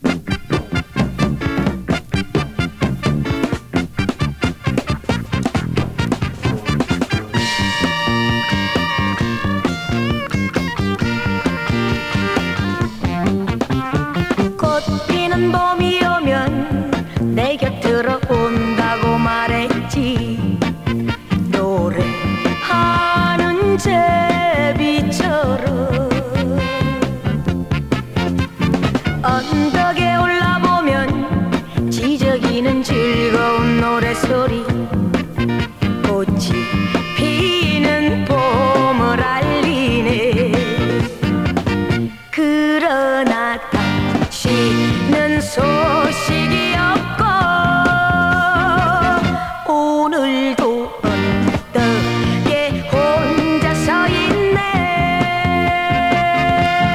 ファンキーなイントロから気合が入ります！ソウルフルな歌声も堪らない。